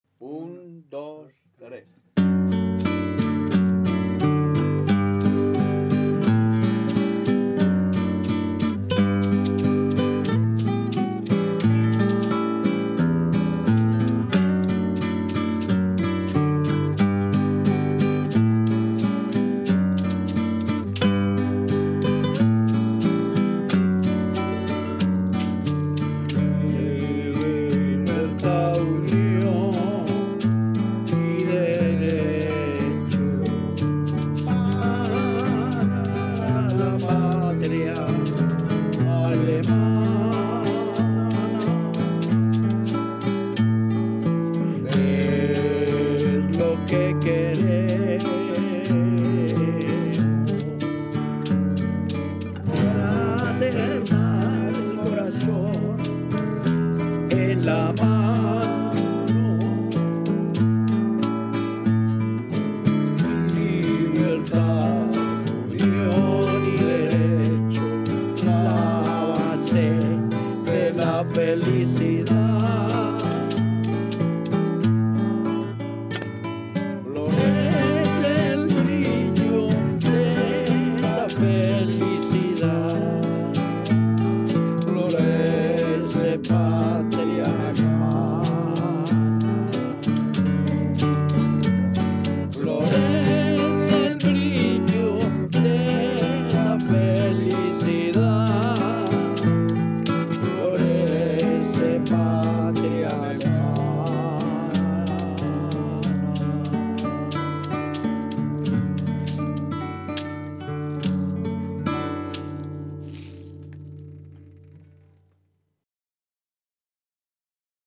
Studio: Universidad del Valle, Cali, Colombia